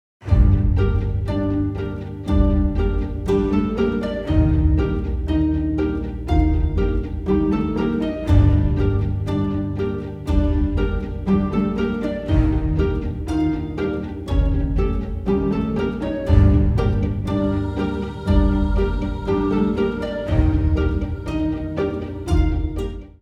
a darkly hypnotic score, suspense writing at its best